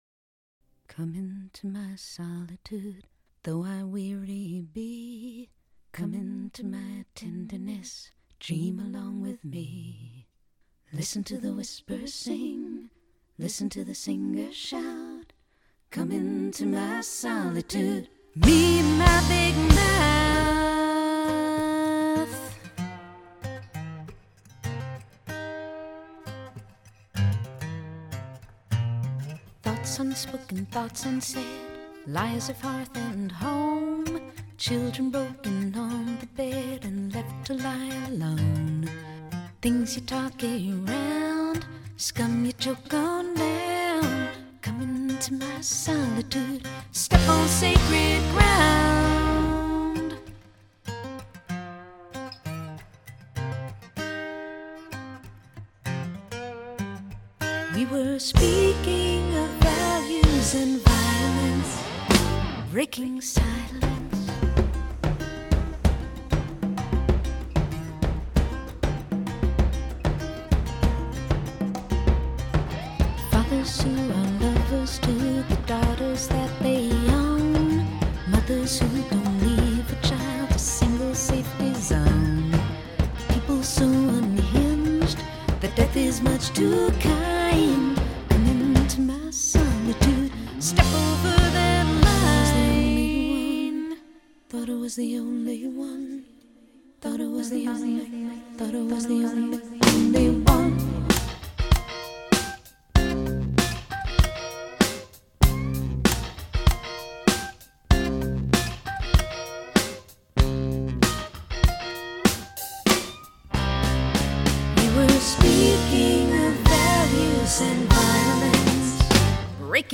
唱片聖經劉漢盛榜單最佳流行音樂腳踩大鼓錄音示範片。
Telefunken U-47 真空管麥克風錄音，聲音醇厚自然。
新版唱片聖經劉漢盛榜單 <專測腳採大鼓與吉他>